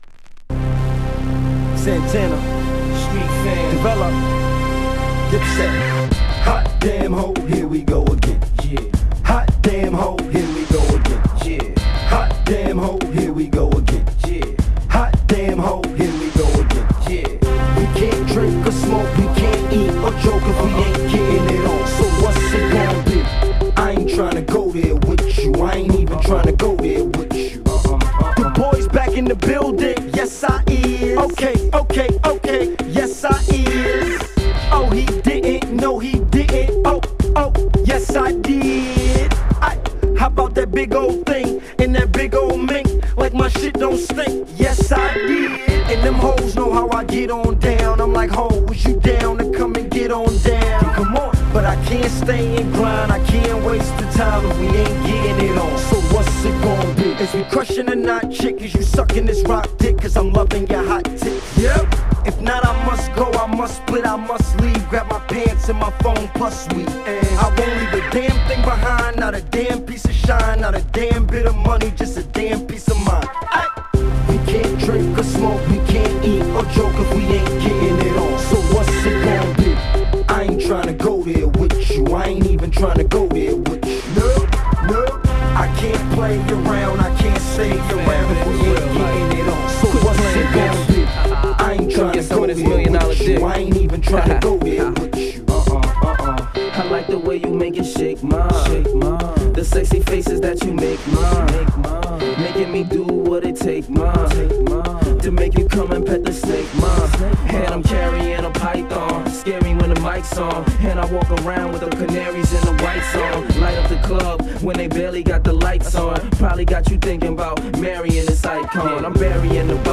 ド迫力のトラックに馴染んだハードコアな2人のフロウもカッコ良すぎるナンバー！